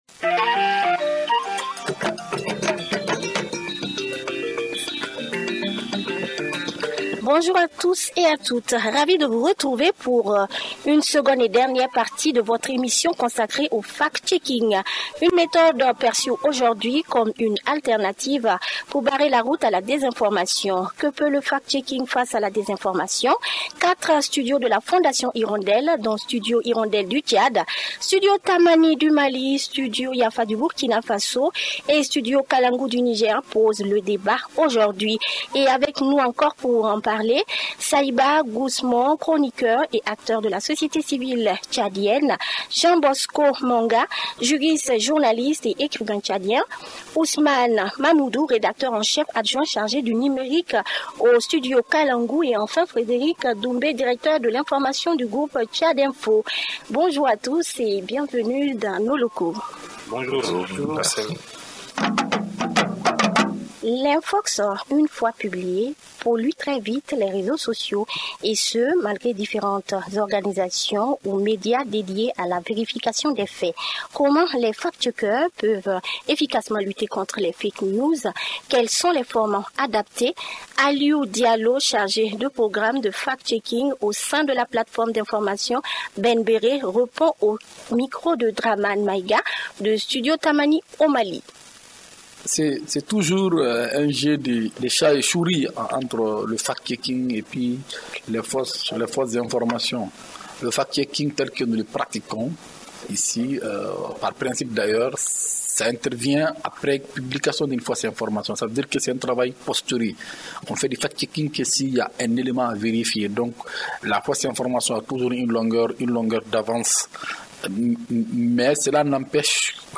Pour en débattre, quatre invités issus des médias et de la société civile :